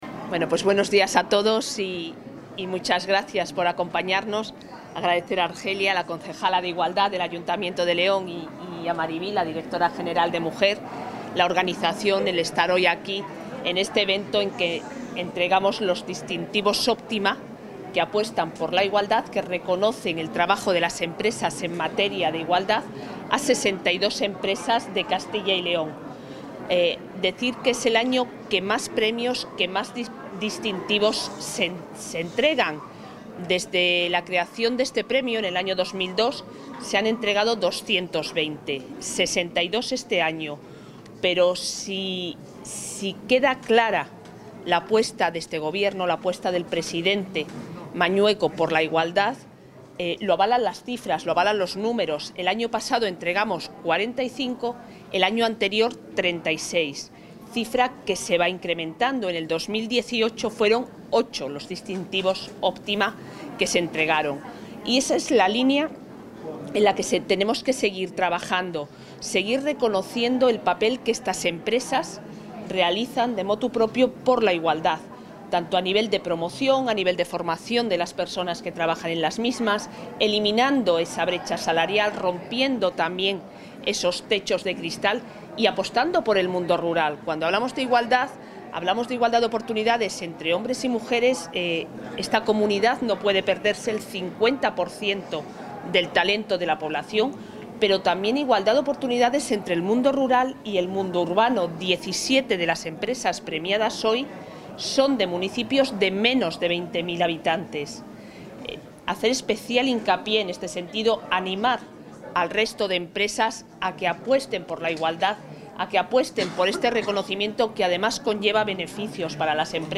Intervención consejera.